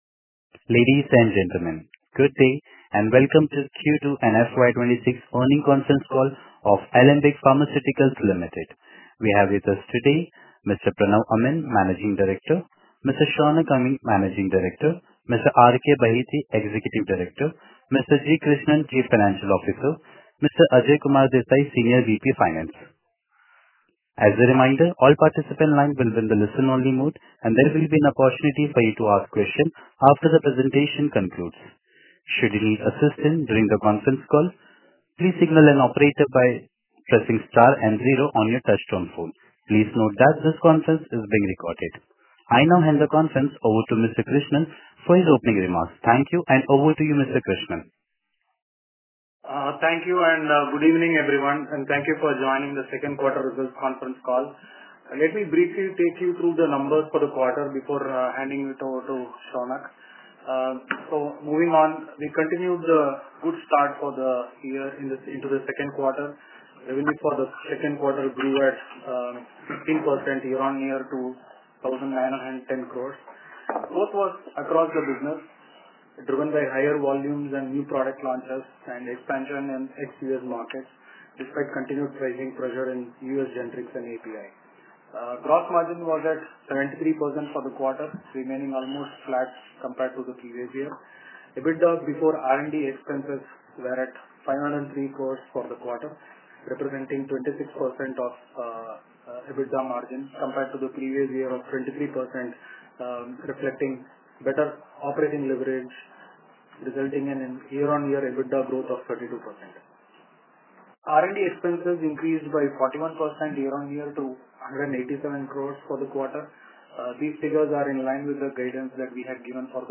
Earning-Conference-Call-4th-November-2025.mp3